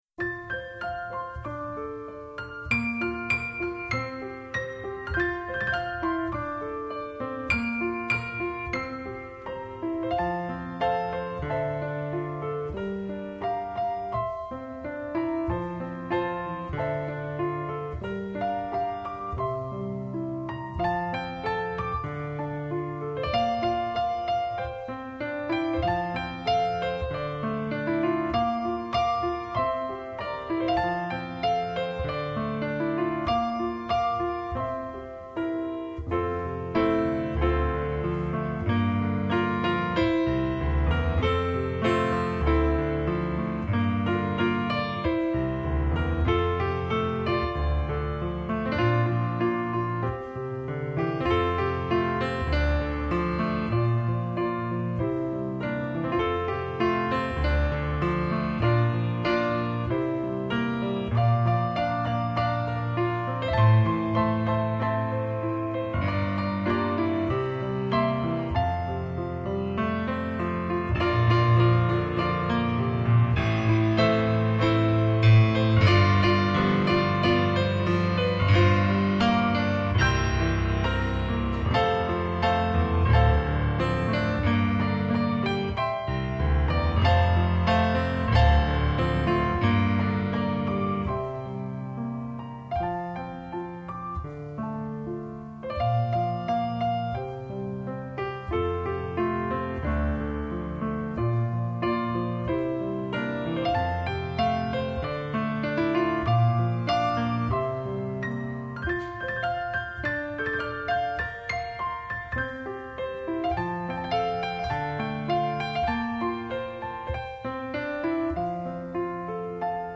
音乐风格: New Age / Piano / Classical